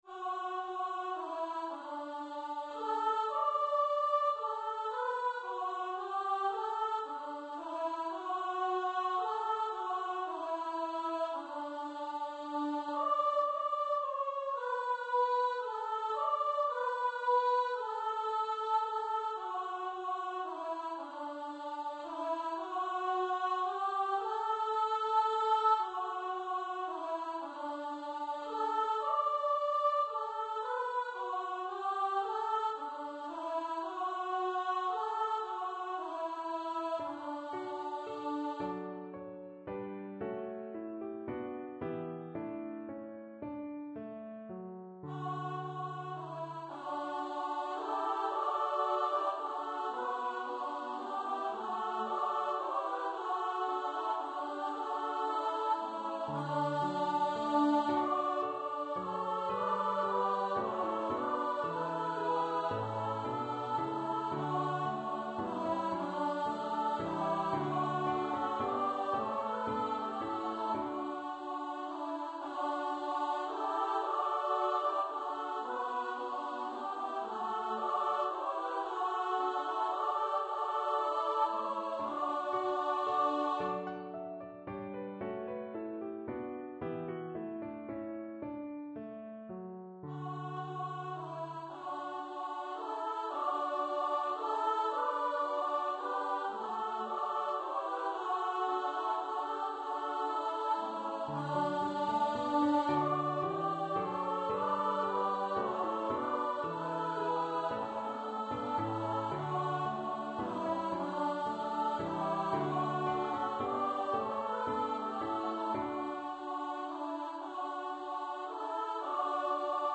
for upper voice choir